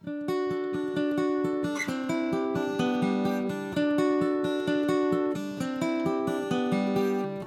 Guitar
guitar.mp3